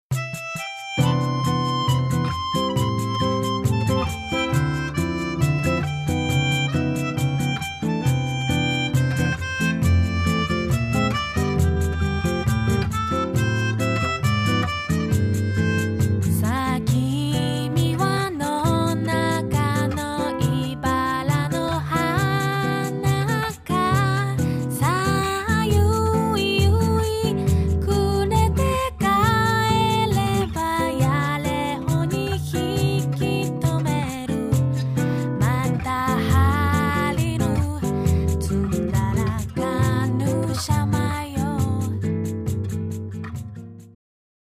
みんなの大好きな沖縄の名曲たちをボサノバで歌いました！